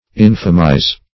Search Result for " infamize" : The Collaborative International Dictionary of English v.0.48: Infamize \In"fa*mize\, v. t. [imp.
infamize.mp3